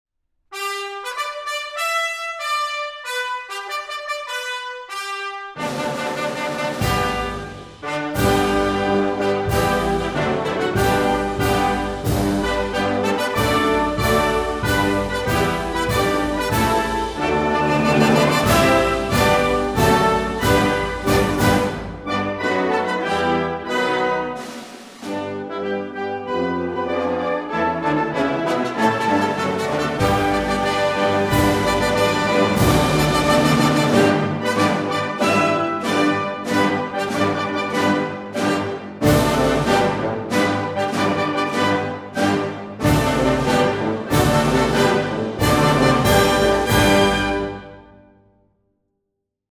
Official Recording of the National Anthem – Band Version